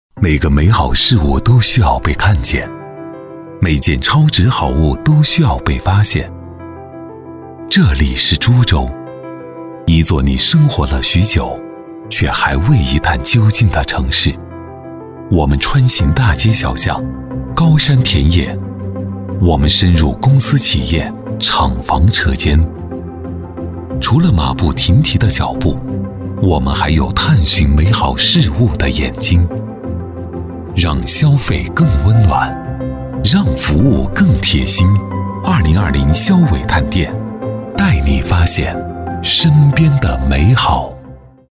男139-城市宣传【株洲 质感 自然温暖讲述】
男139-城市宣传【株洲 质感 自然温暖讲述】.mp3